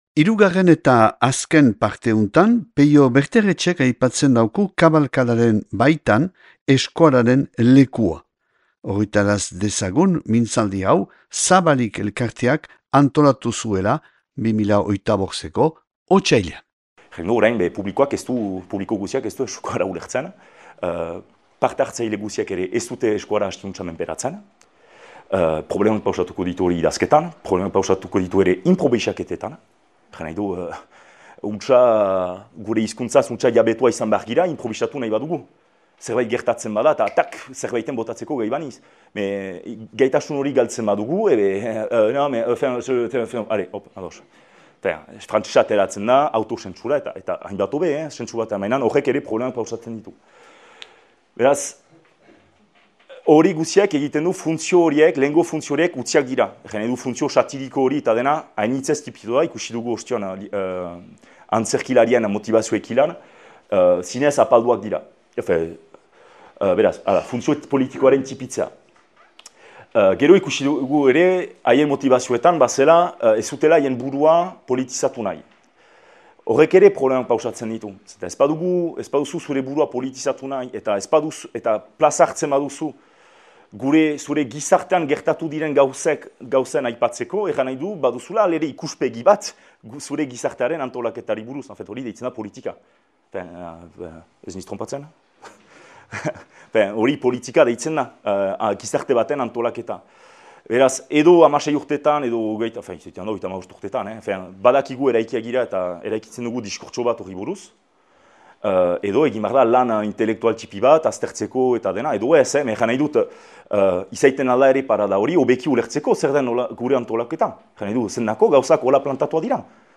(2025. otsailaren 20an grabatua Donapaleun « Otsail Ostegunak » hitzaldiaren zikloan Zabalik elkarteak.